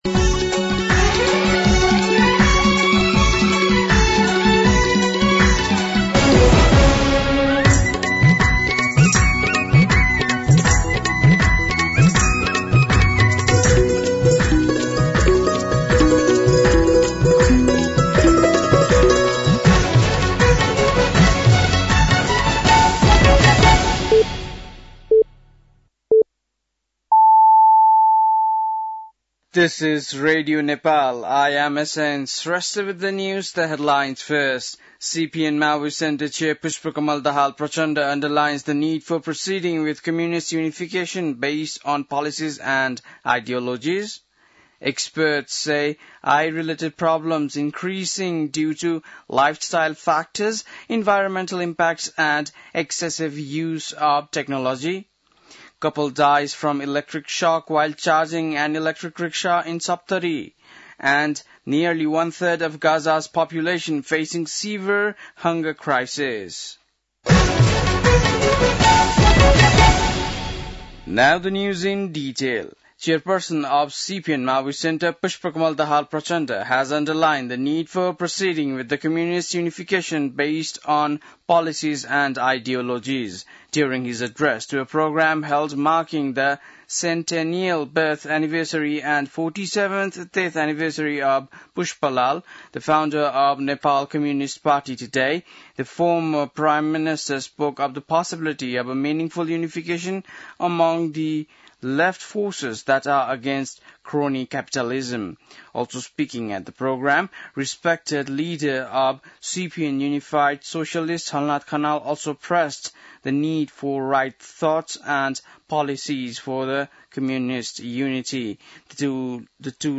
An online outlet of Nepal's national radio broadcaster
बेलुकी ८ बजेको अङ्ग्रेजी समाचार : १० साउन , २०८२